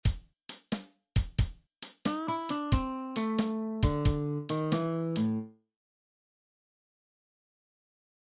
Hier sind 10 Licks im ⁴/₄-Takt in A-Dur.
Als rhythmische Orientierung dient entweder das Metronom oder das Schlagzeug.